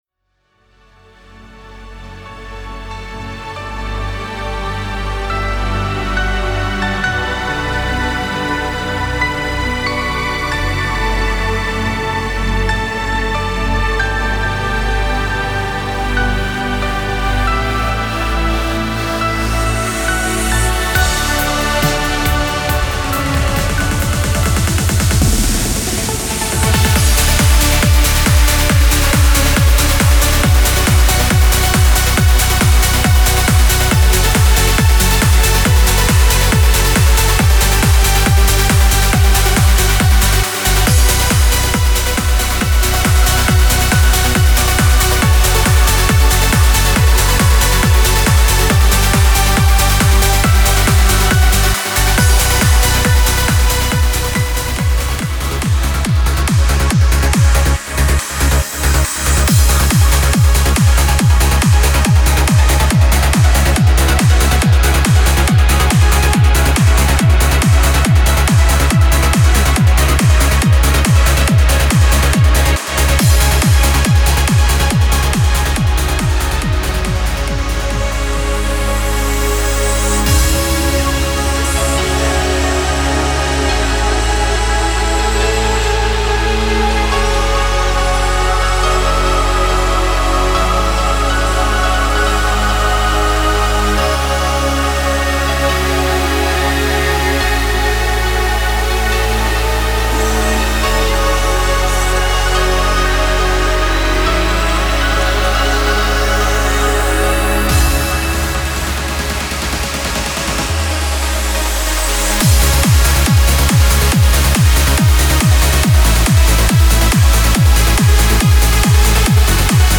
Genre: Trance Uplifting Trance